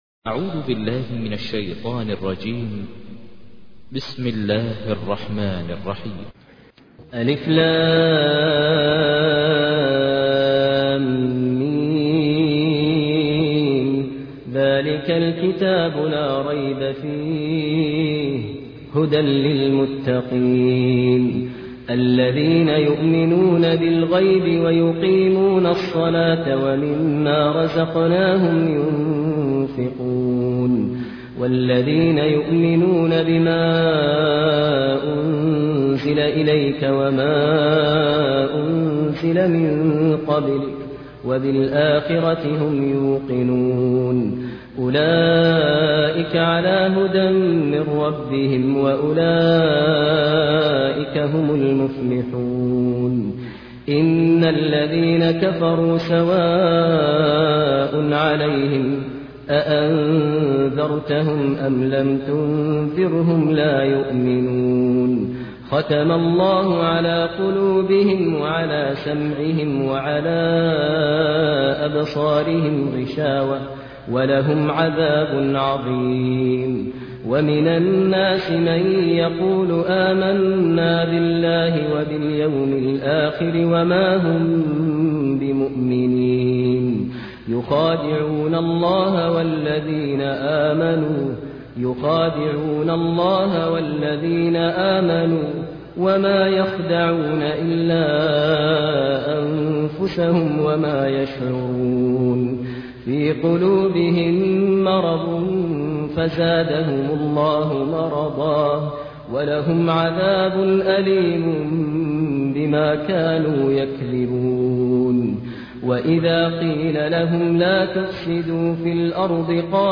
تحميل : 2. سورة البقرة / القارئ ماهر المعيقلي / القرآن الكريم / موقع يا حسين